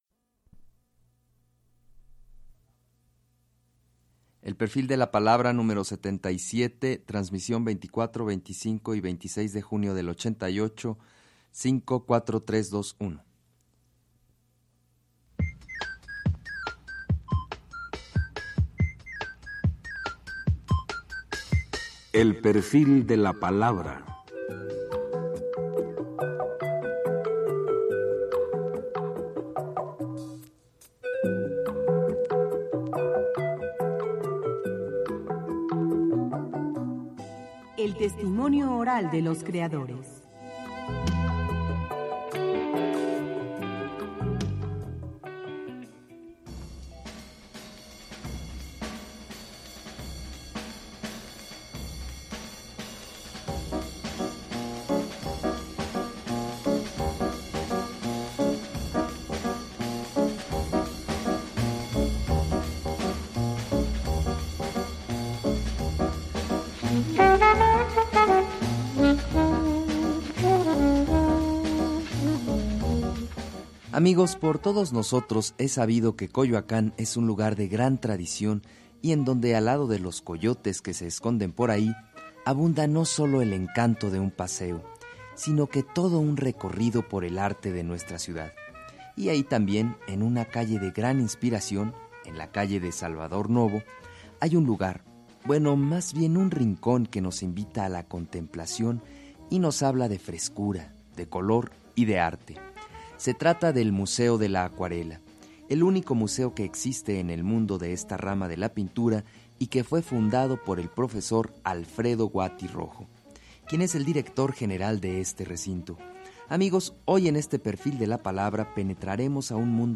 Escucha al acuarelista Alfredo Guati Rojo en el programa “El perfil de la palabra”, transmitido en 1988.